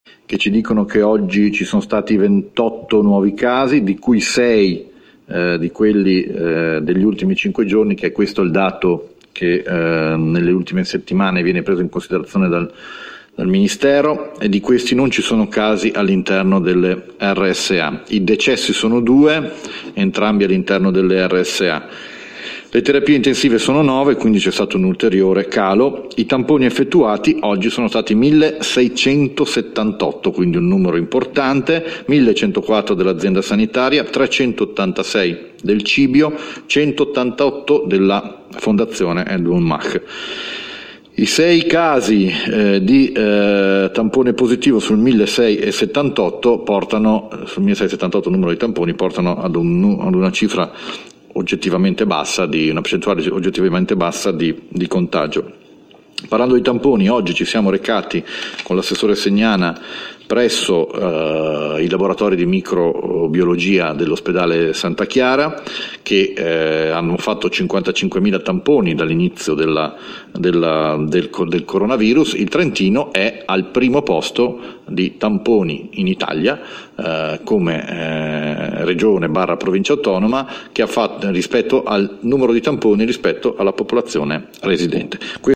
Ascolta il presidente Fugatti (AUDIO)